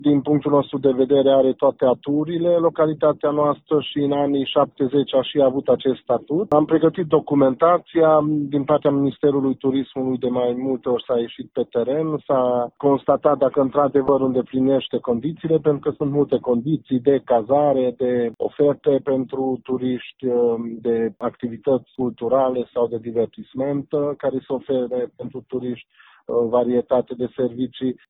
Localitatea Sângeorgiu de Mureș a fost inclusă pe această listă după o examinare detaliată și o vizită pe teren a oficialilor de la Minister, a declarat primarul comunei, Sofalvi Szabolcs: